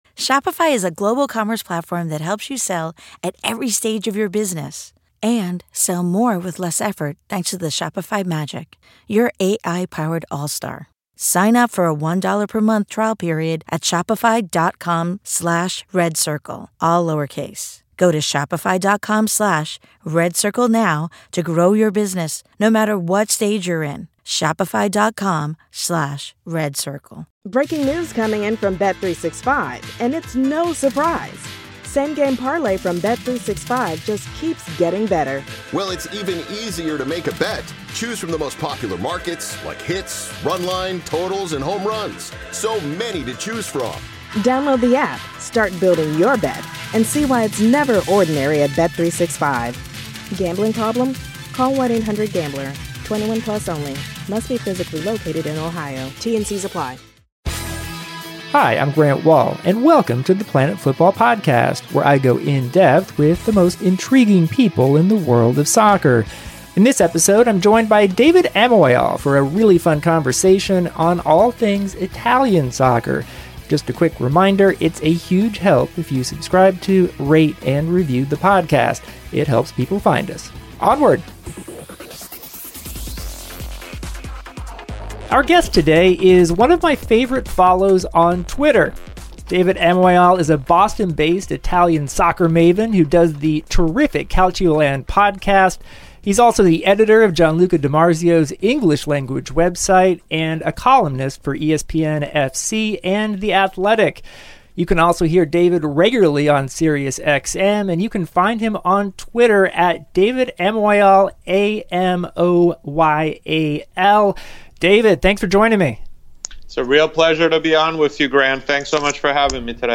Grant interviews